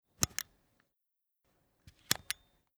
Akkuschrauber IXO 6
Richtungsschalter betätigen
58958_Richtungsschalter_betaetigen.mp3